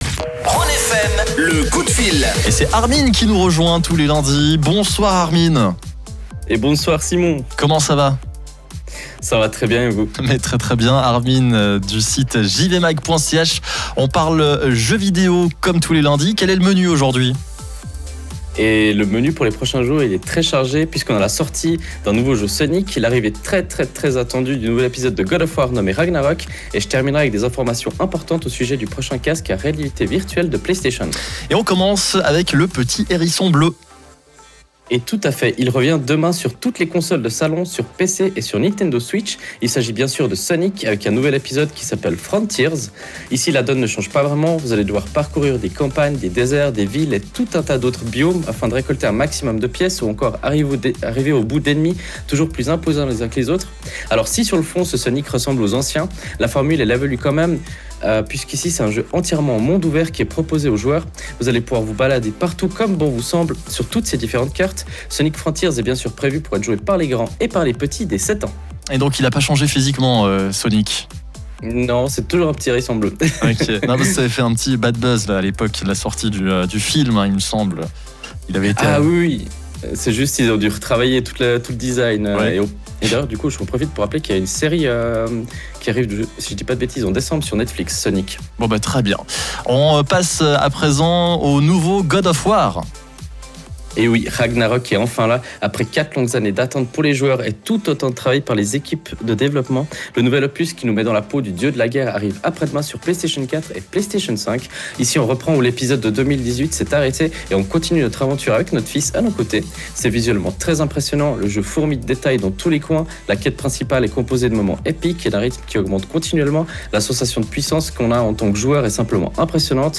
Pour réécouter le direct, ça se passe via le player en dessus.